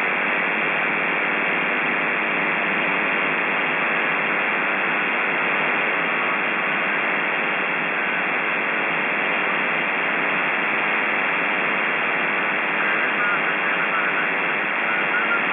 いずれもSSBで復調したWAVファイルです。